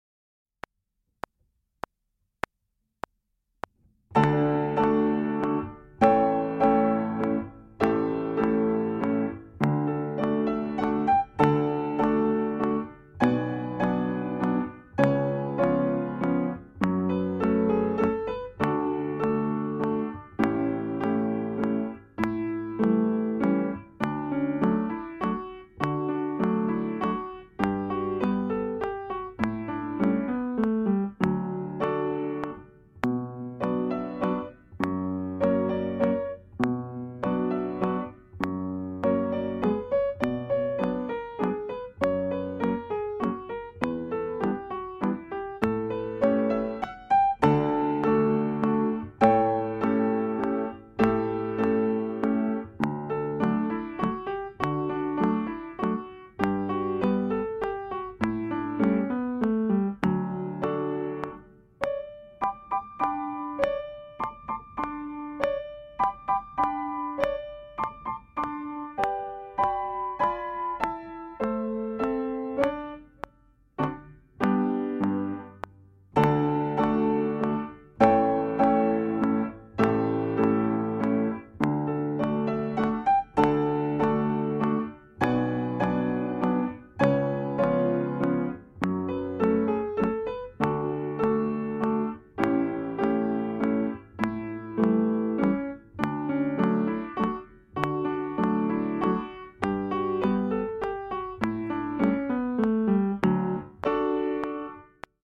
I wersja – Tempo 100bmp (wersja ćwiczeniowa z metronomem)
Nagranie dokonane na pianinie Yamaha P2, strój 440Hz
piano